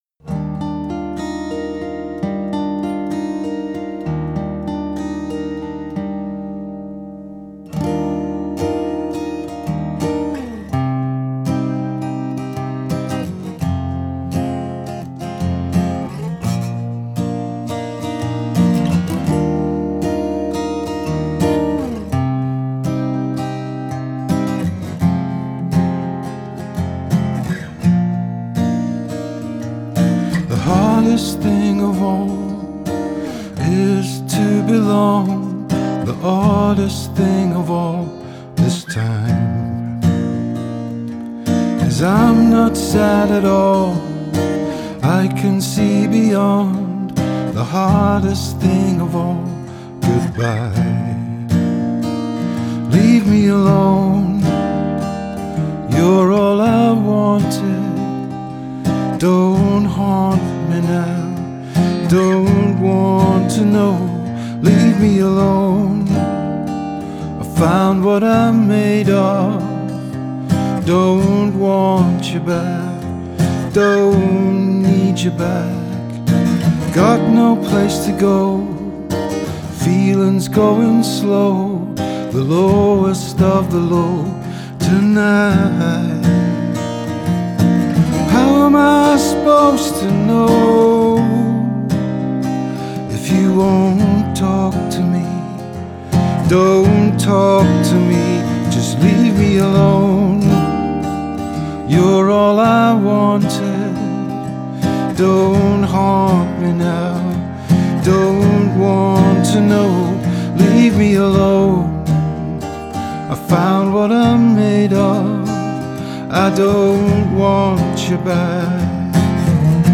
poignant contemplation of the day to day.
Singer-songwriter